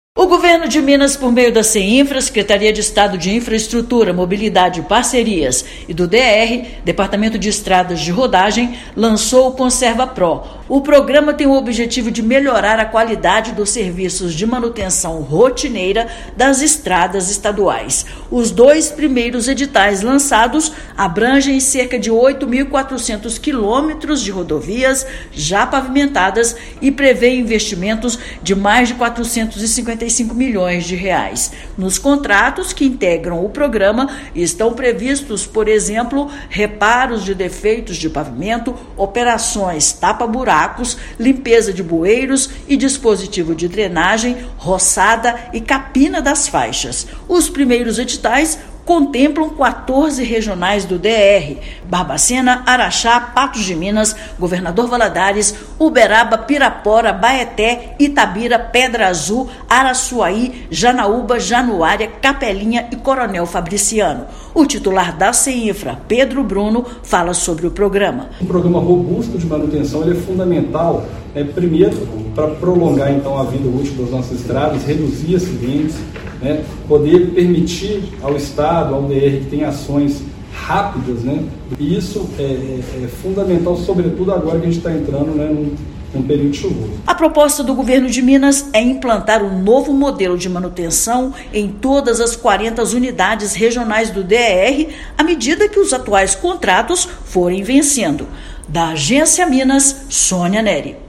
ConservaPro tem como foco ampliar a segurança dos mineiros no deslocamento pelas estradas; nos próximos dois anos, serão feitas melhorias em cerca de 8,4 mil quilômetros de rodovias. Ouça matéria de rádio.